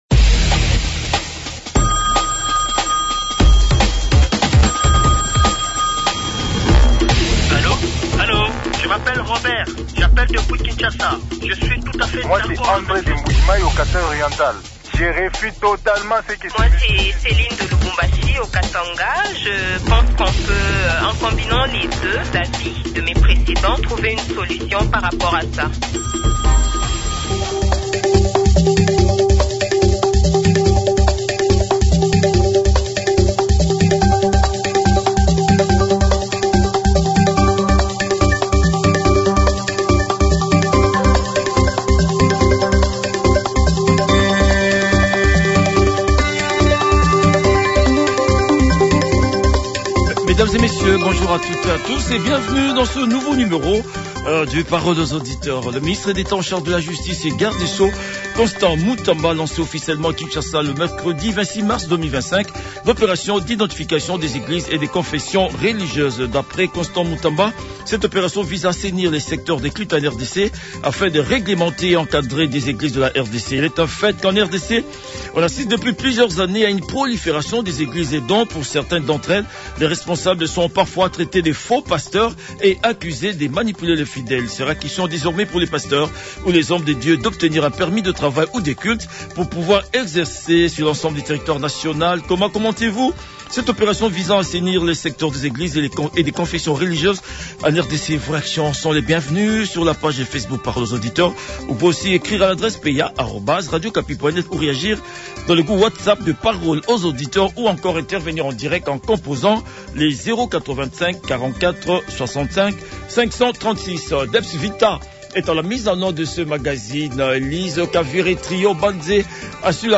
a débattu de ce sujet avec les auditeurs